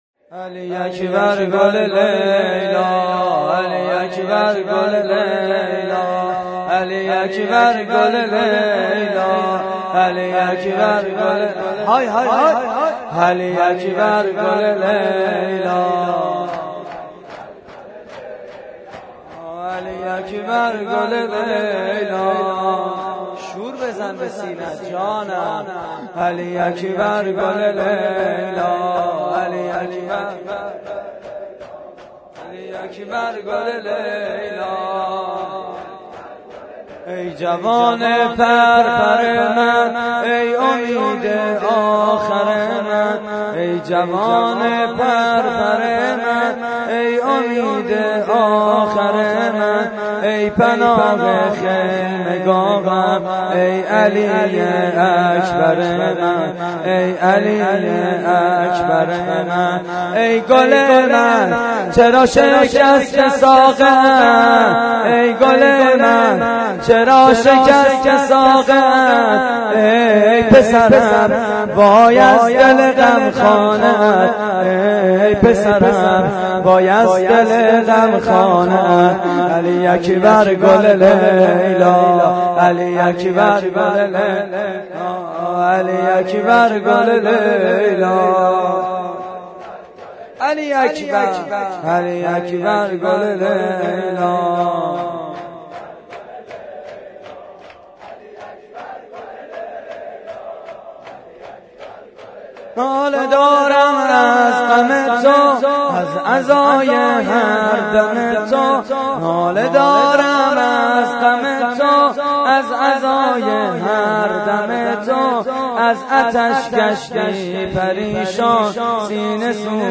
مراسم عزاداری شب هشتم ماه محرم / هیئت کریم آل طاها (ع) – شهرری؛ 3 دی 88
صوت مراسم:
شور: علی اکبر گل لیلا؛ پخش آنلاین |